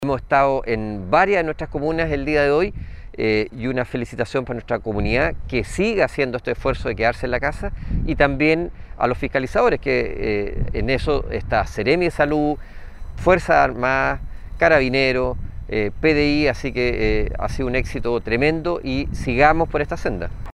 El seremi Alejandro Caroca resaltó este comportamiento de la comunidad de Los Lagos, porque el objetivo es ayudar a disminuir los números de contagios, manifestó el personero del Minsal.